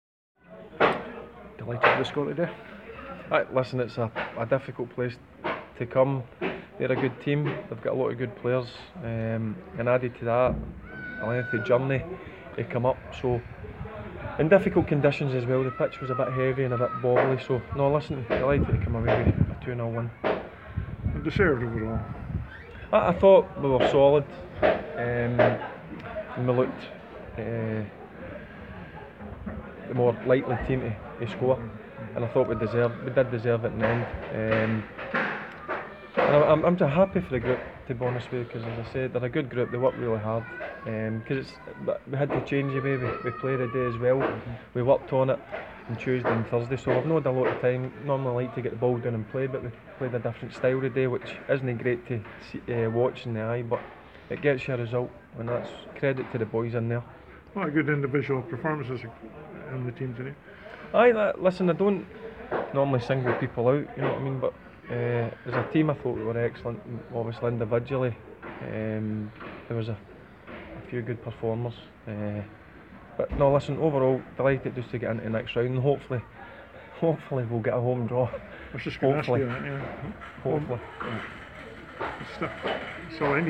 Barry Ferguson's press conference after the William Hill Scottish Cup match.